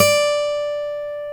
Index of /90_sSampleCDs/Roland L-CD701/GTR_Steel String/GTR_ 6 String
GTR 6 STR B5.wav